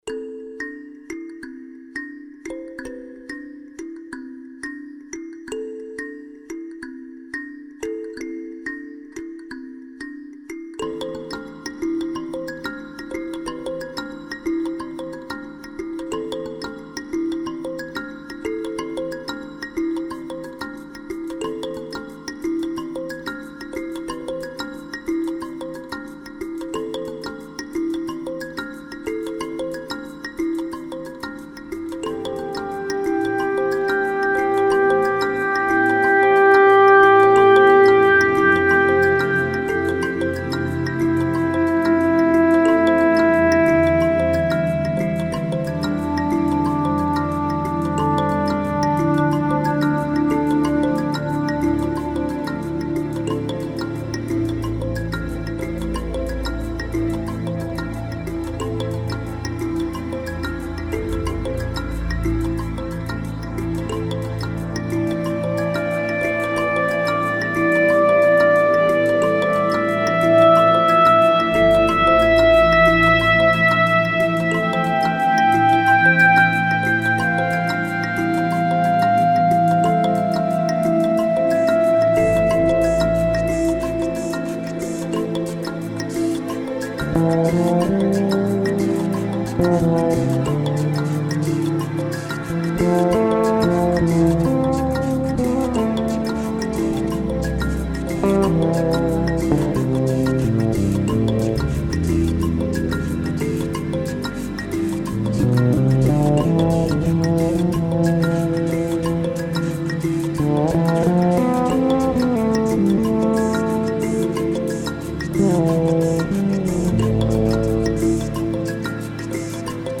flugelhorn or trumpet